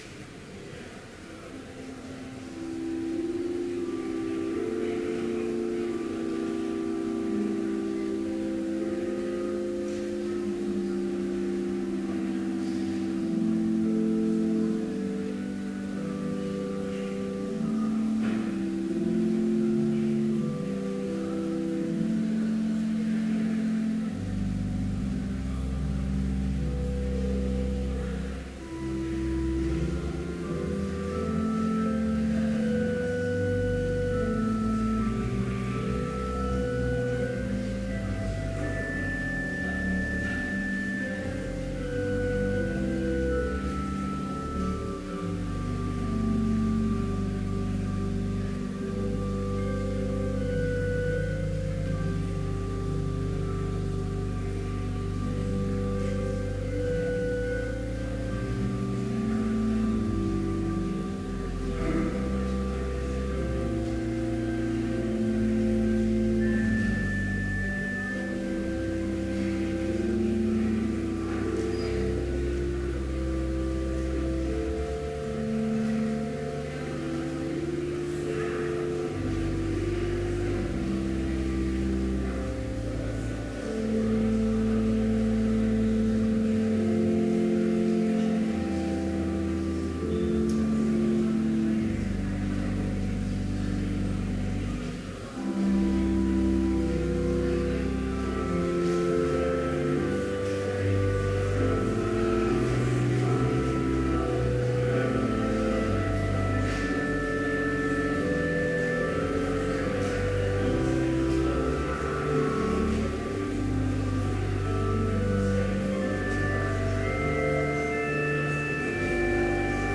Complete service audio for Chapel - October 22, 2014